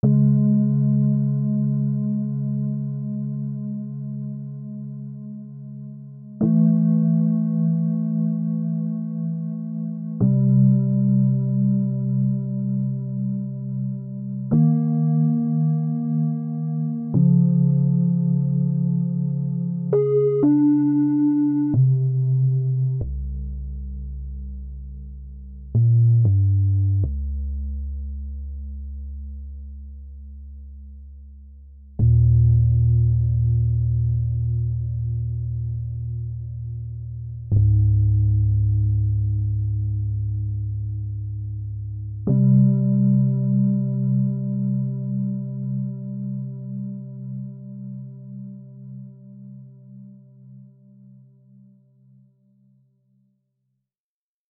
First try. Not really close I guess.
There is a small resemblance of a Rhodes sound on the lower notes I guess.
It’s way easier to get into organ-like territory. And as expected, lower registers sound better.